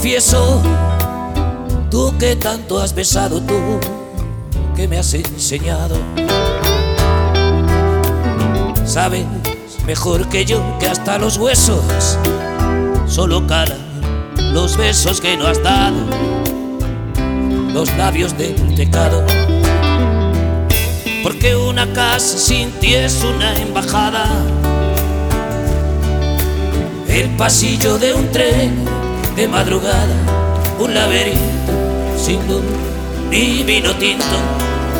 Latin Pop Britpop Pop Latino
Жанр: Поп музыка